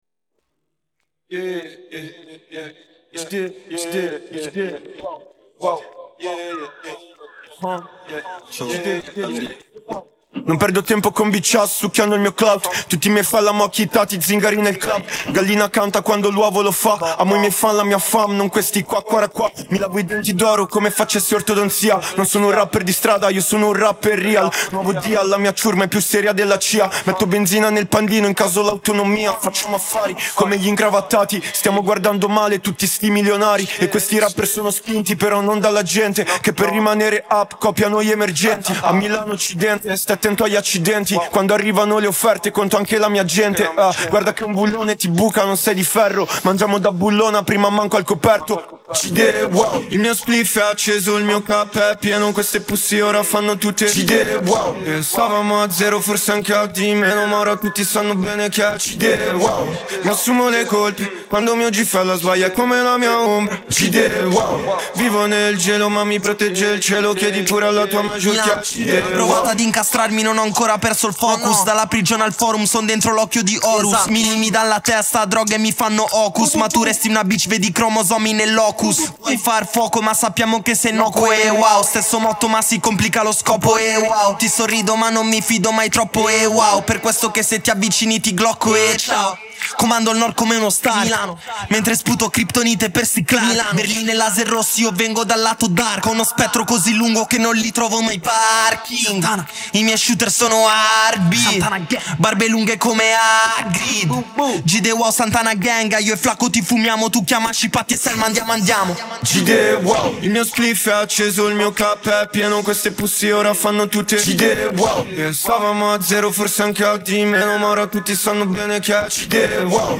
Stimme